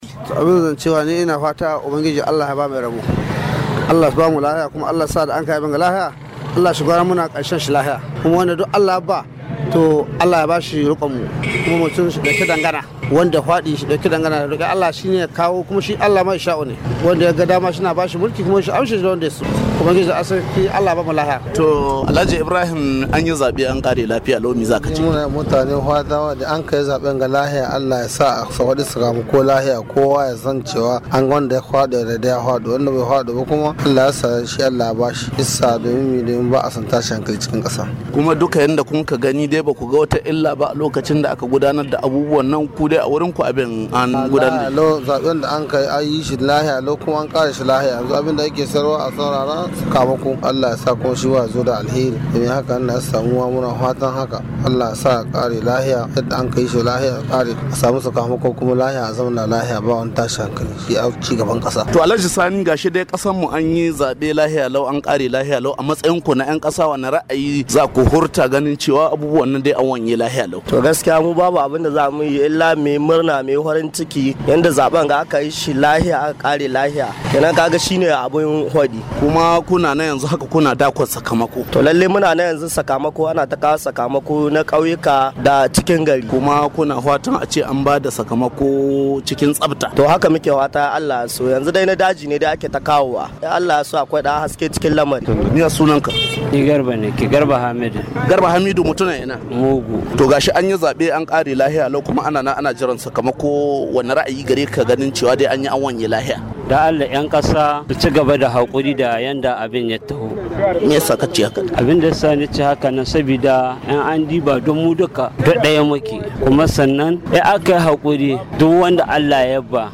Bayan an kammala zaben kasar Nijar Muryar Amurka ta zagaya birnin Kwanni ta ji ta bakin mutane dangane da zaben da aka gudanar ranar Lahadi da ta gabata